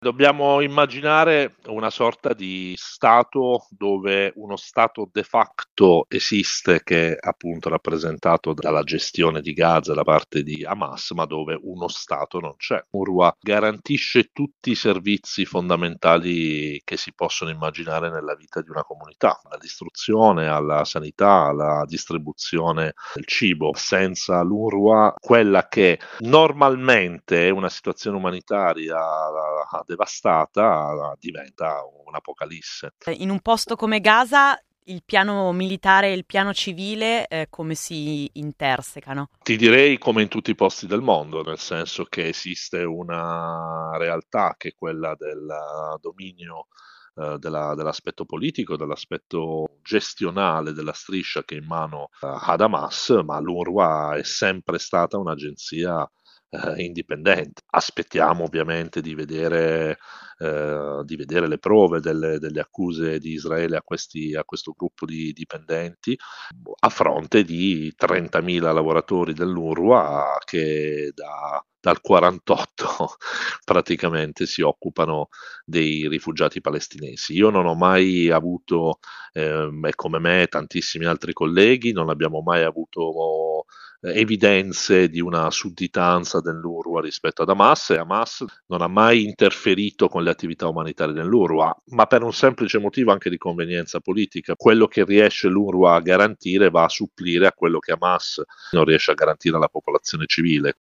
giornalista che ha lavorato spesso nei territori palestinesi